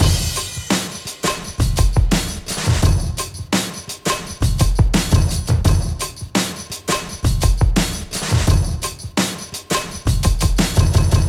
Бочка, работник и тамбурин - это я понимаю. А между 2 и 3 долей, на слабую что-то бьёт не совсем мне понятное.